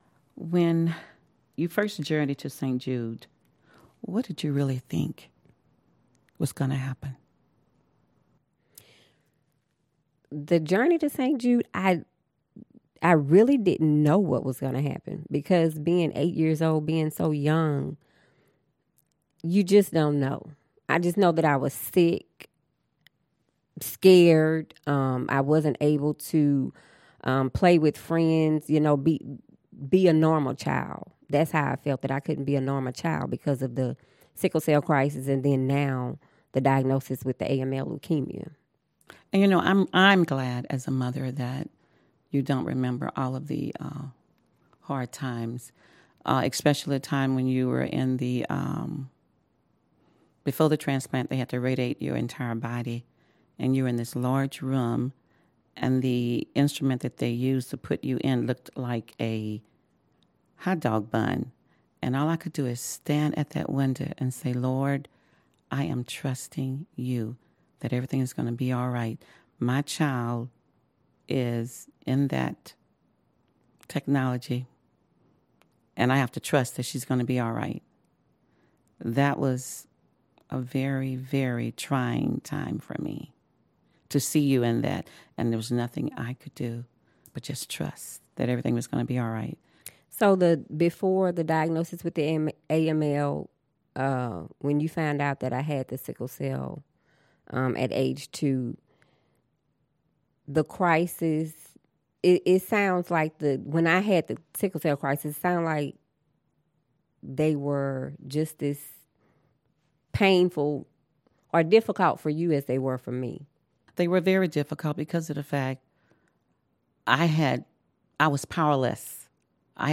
Partnering with the nonprofit oral history project StoryCorps, we asked families, faculty and staff to interview each other and record their shared moments in St. Jude history.